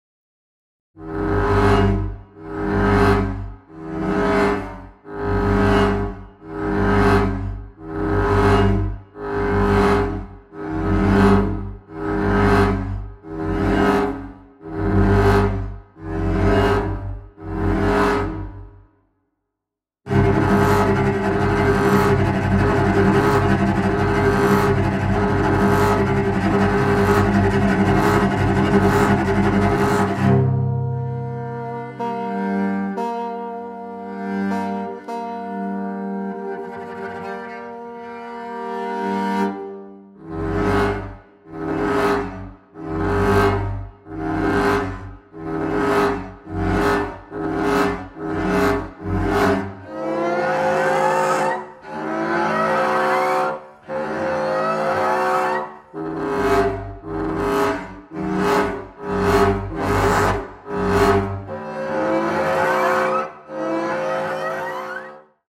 bassoon